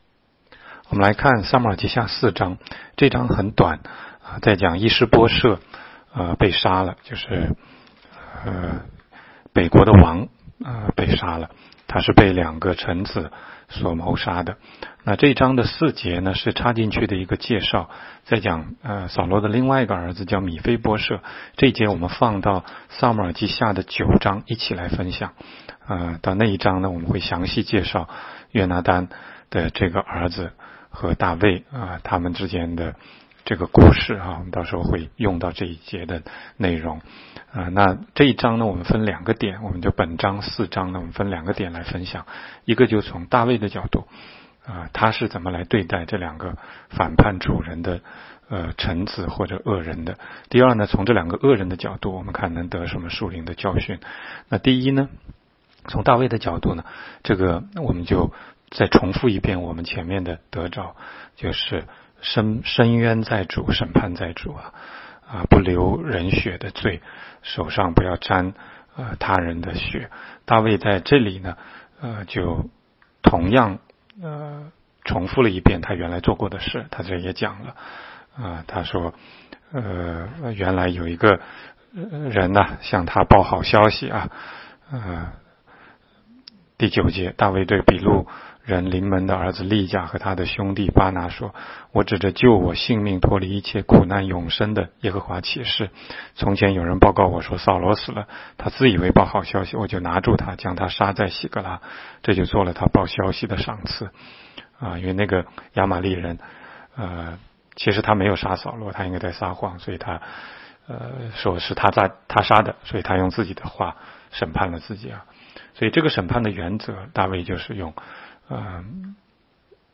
16街讲道录音 - 每日读经-《撒母耳记下》4章